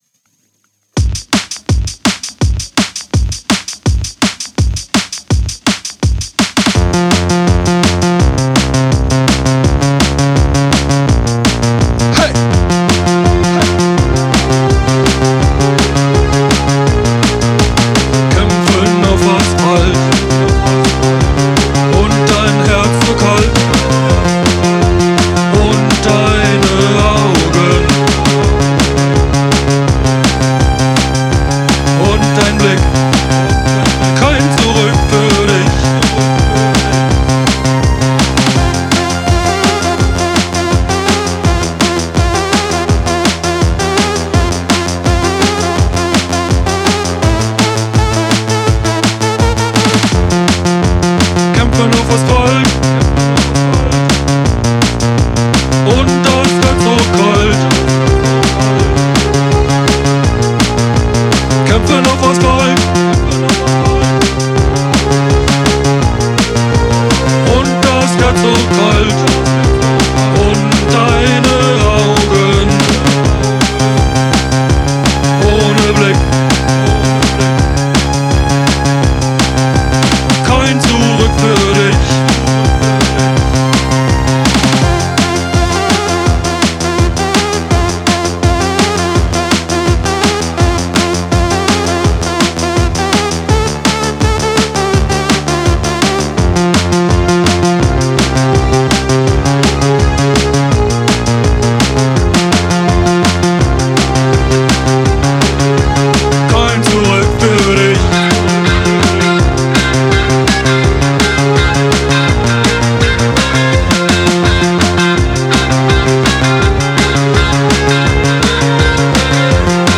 Actung: großer Synthesizer